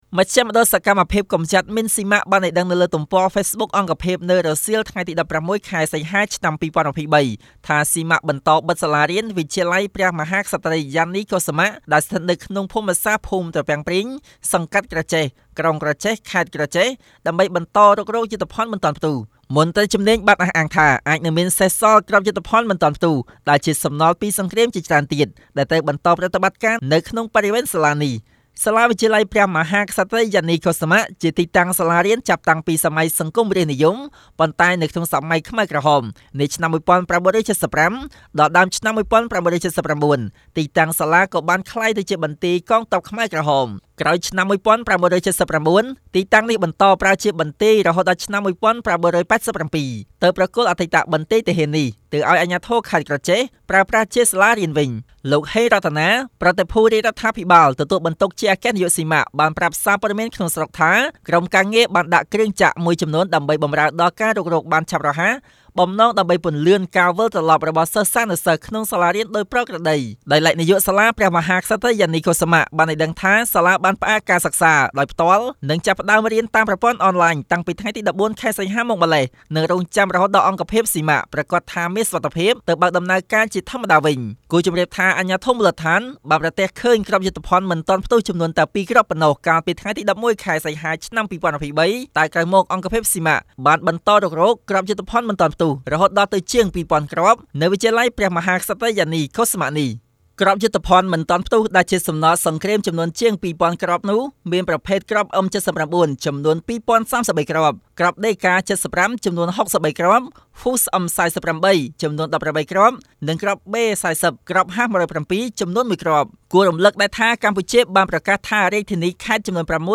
ជូននូវសេចក្ដីរាយការណ៍